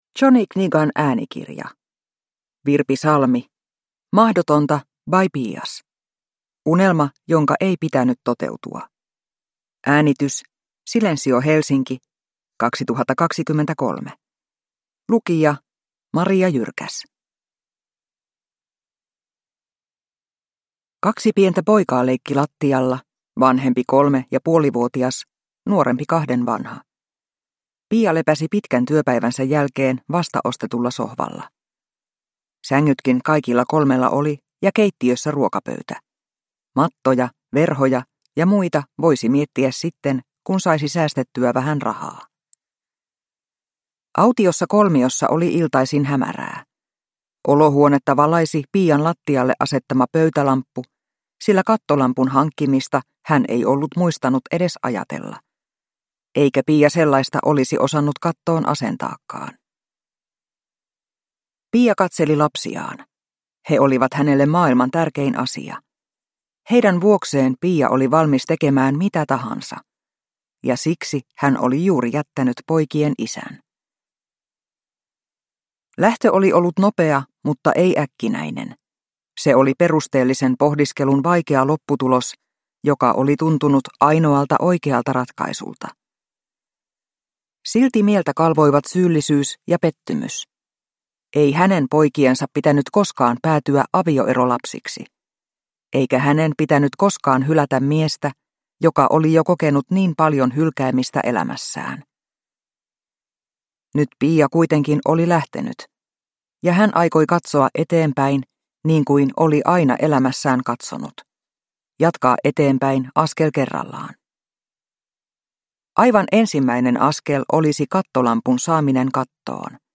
Mahdotonta (ljudbok